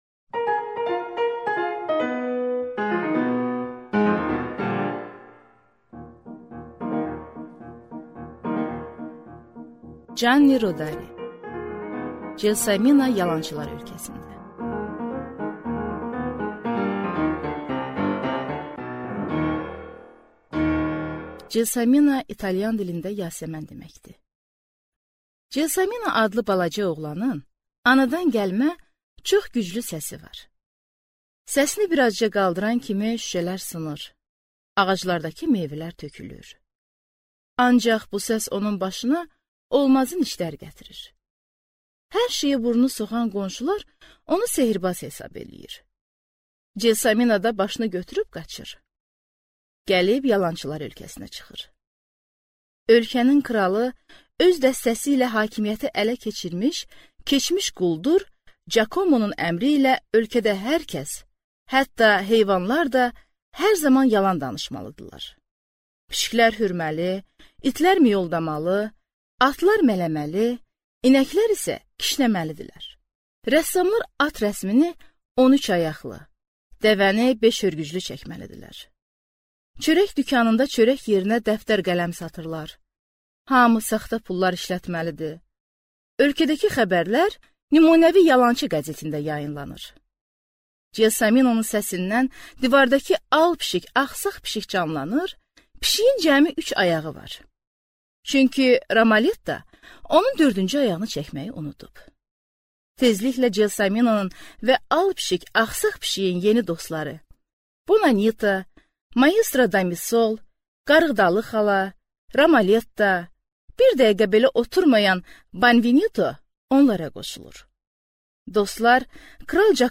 Аудиокнига Celsomino yalançılar ölkəsində | Библиотека аудиокниг
Прослушать и бесплатно скачать фрагмент аудиокниги